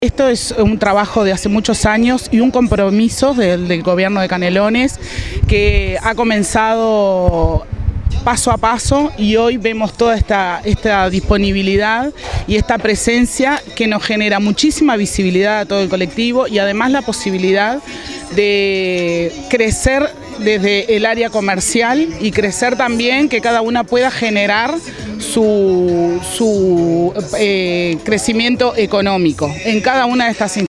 Se realizó el lanzamiento departamental del Mes de la Afrodescendencia en el Parque Roosevelt.